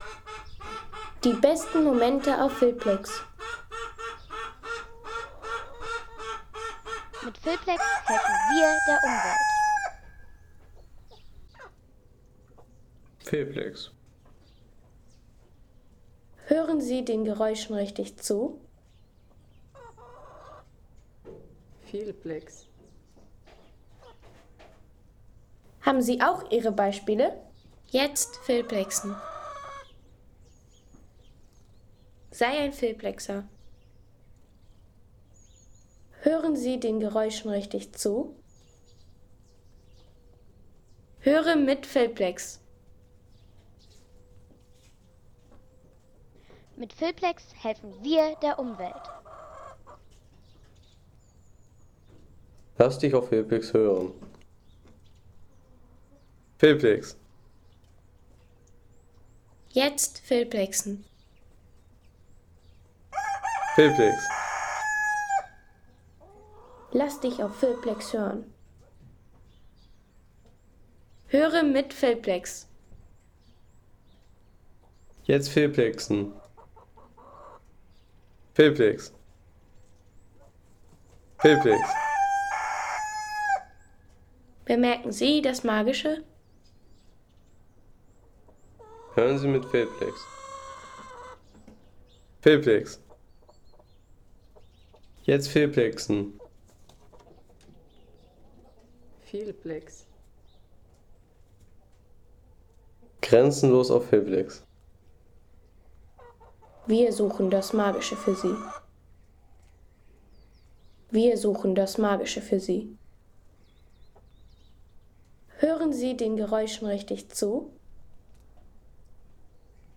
Erholungsgenuss für alle, wie z.B. diese Aufnahme: Kikeriki im Hühnerstall
Kikeriki im Hühnerstall